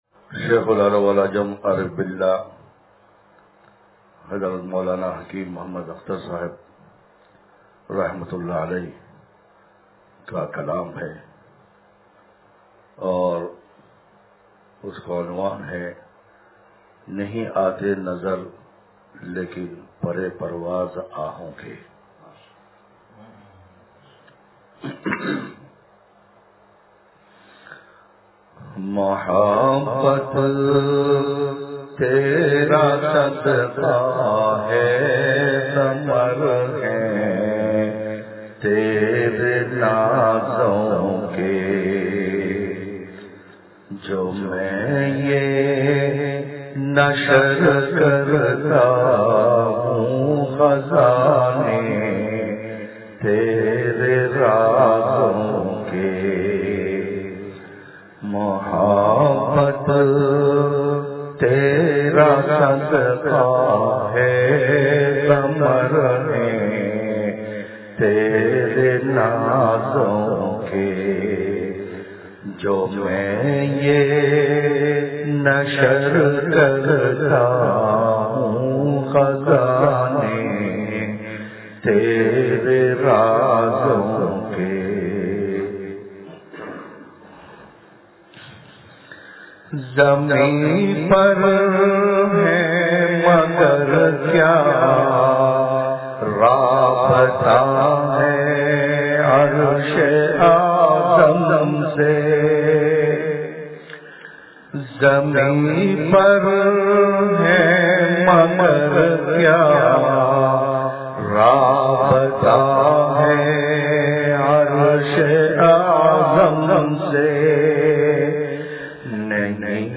نہیں آتے نظر لیکن پرِ پرواز آہوں کے – مجھ کو جینے کا سہارا چاہیئے – مجلس بروز اتوار